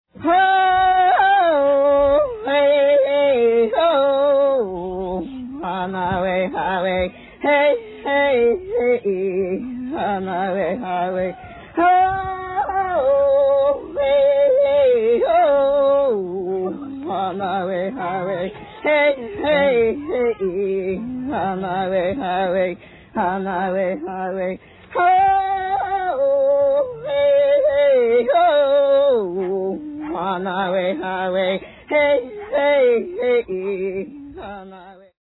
Feminine Solo Chant - :50